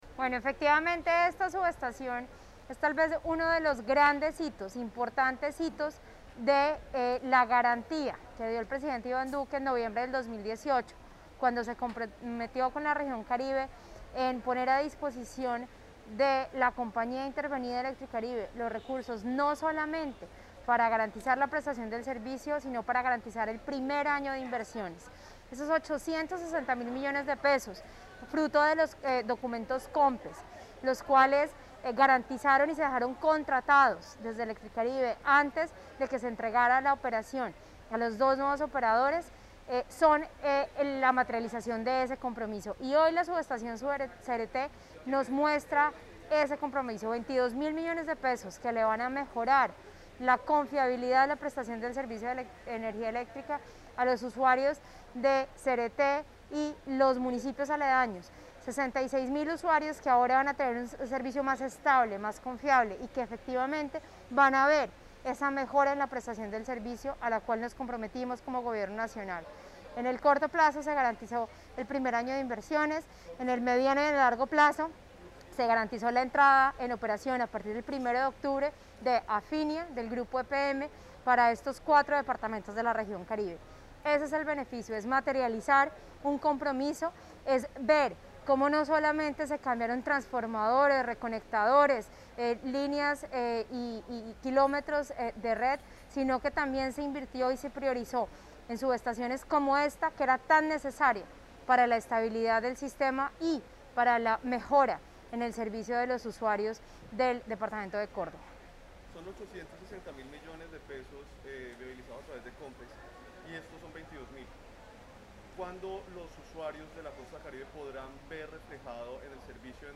Declaraciones de la superintendente Natasha Avendaño García
declaraciones_-_superintendente_natasha_avendano.mp3